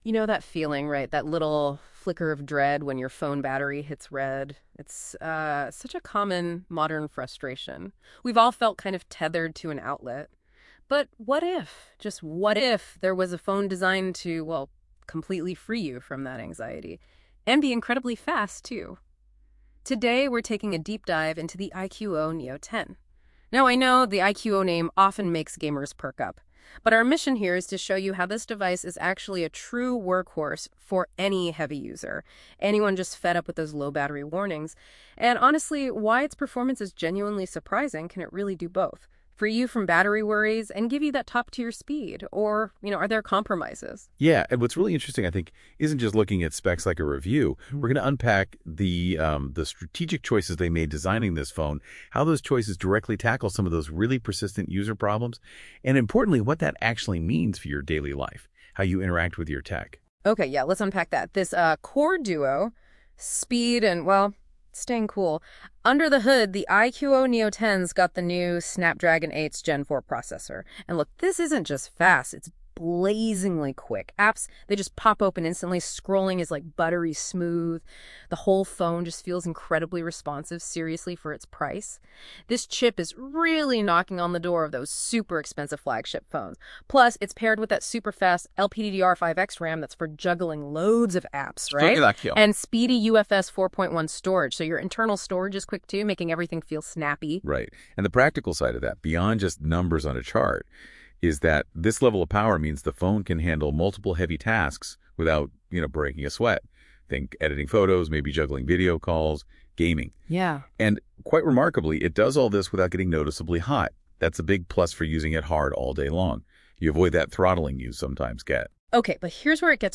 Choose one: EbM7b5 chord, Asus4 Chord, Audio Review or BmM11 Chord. Audio Review